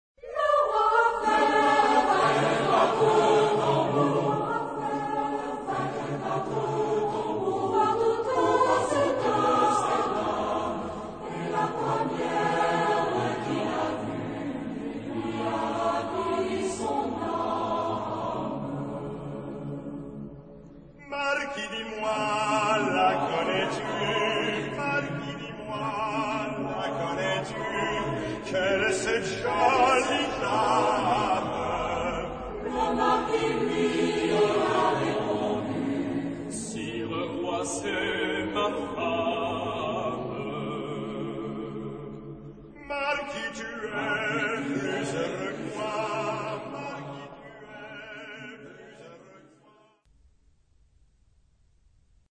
Genre-Style-Form: Folk music ; Secular ; Popular
Type of Choir: SATB  (4 mixed voices )
Tonality: D minor
Origin: Saintonge (F)